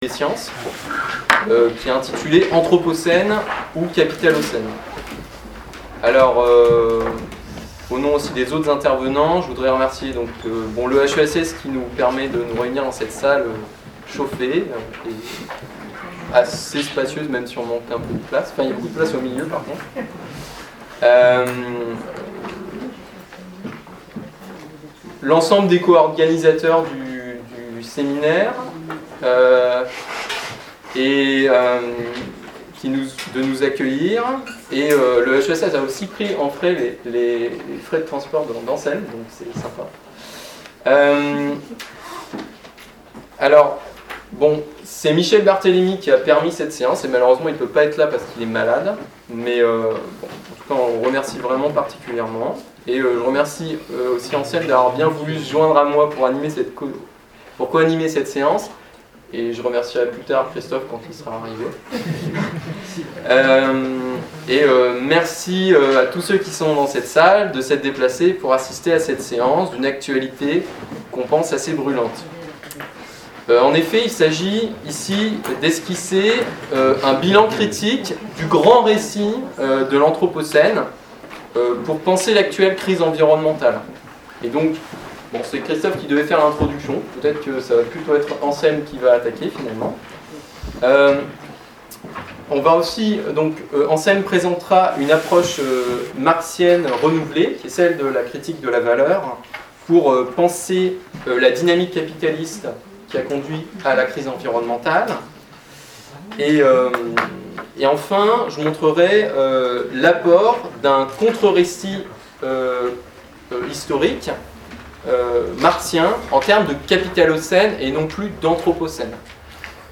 L'intervention